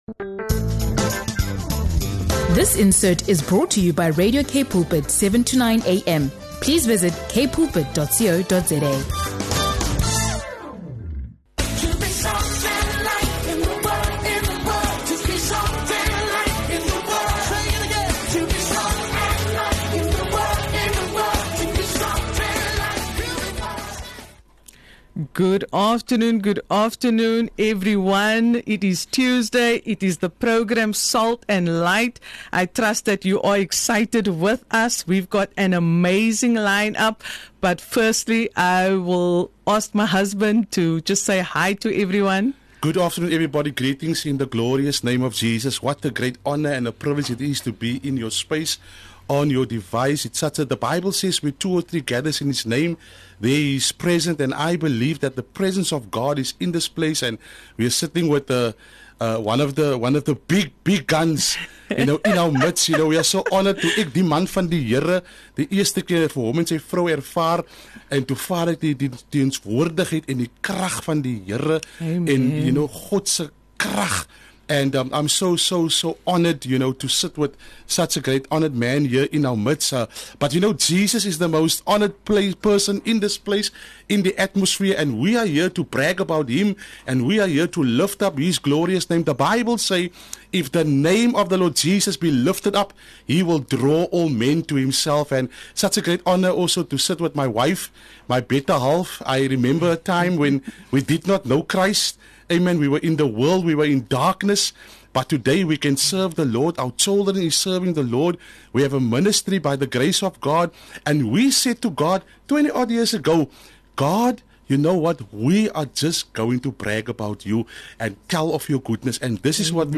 Tune in for a faith-stirring conversation that builds expectation for revival in Cape Town and beyond.